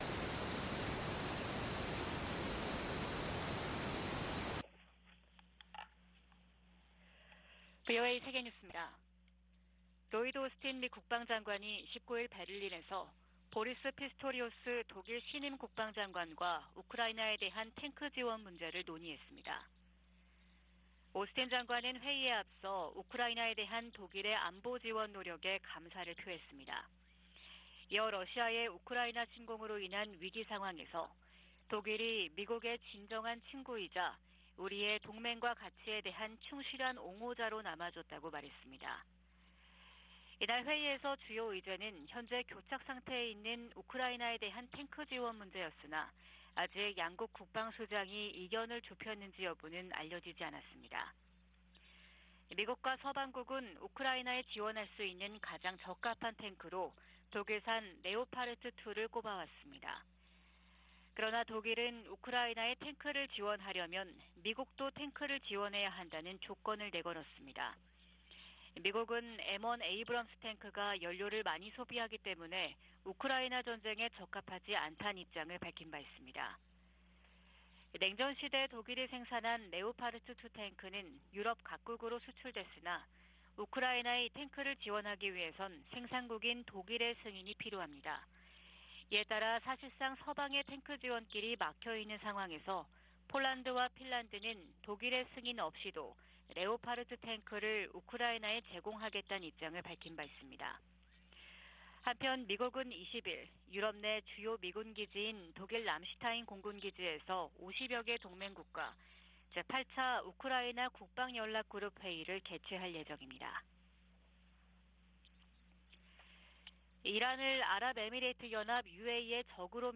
VOA 한국어 '출발 뉴스 쇼', 2023년 1월 20일 방송입니다. 북한이 핵보유국을 자처해도 미국의 한반도 비핵화 목표에는 변함이 없다고 국무부가 밝혔습니다. 김정은 국무위원장이 불참한 가운데 열린 북한 최고인민회의는 경제난 타개를 위한 대책은 보이지 않고 사상 통제를 강화하는 조치들을 두드러졌다는 분석이 나오고 있습니다.